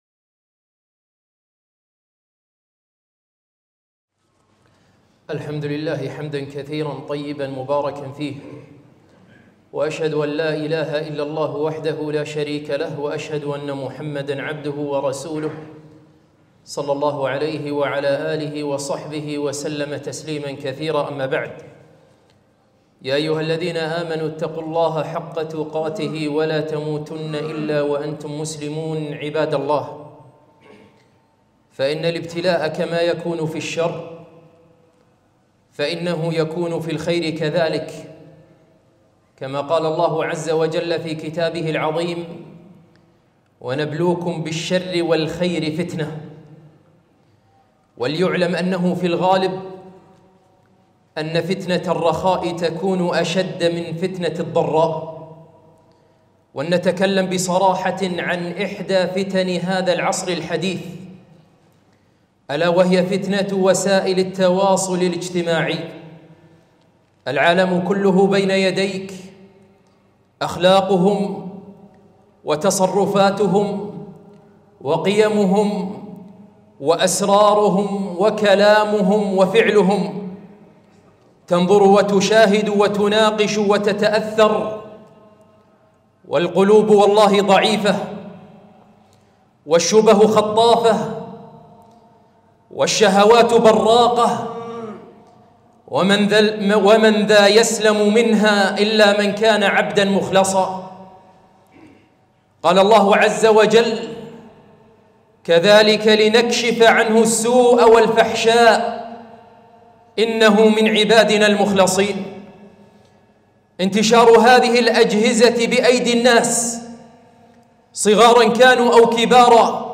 خطبة - برامج ومقاطع هدمت أخلاقنا